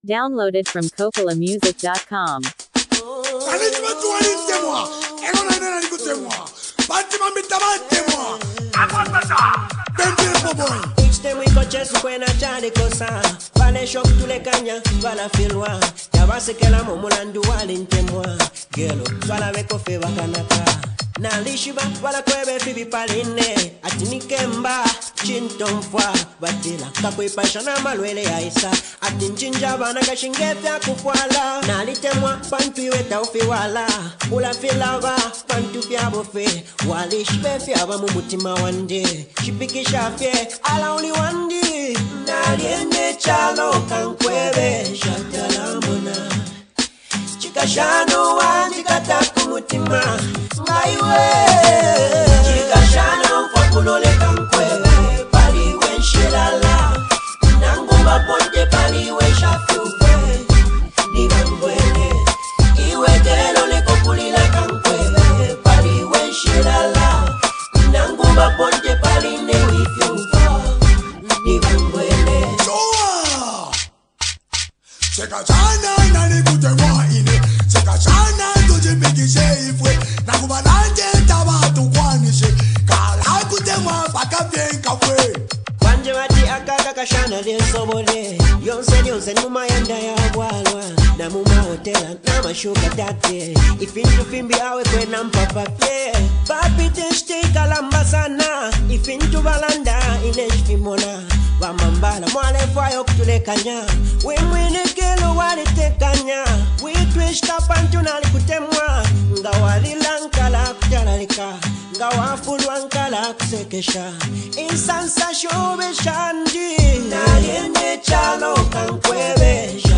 vibrant and culturally rooted song